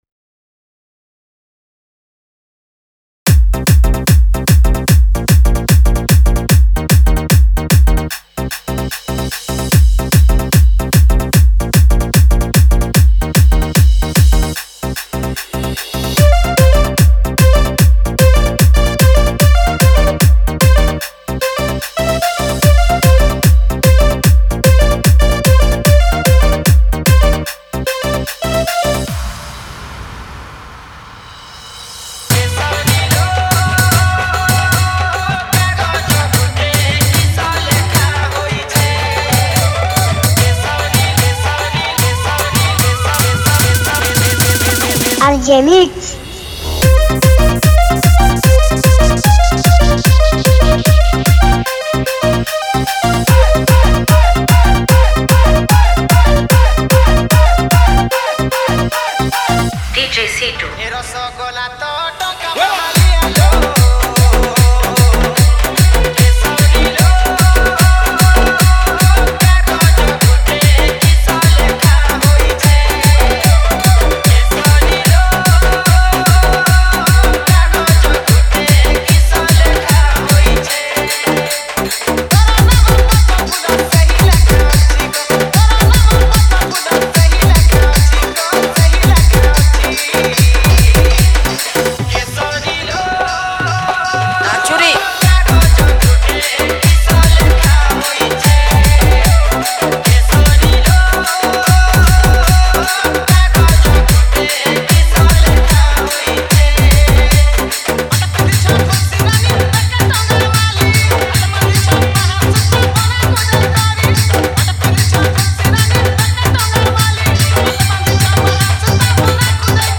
Category:  Sambalpuri New Dj Song 2019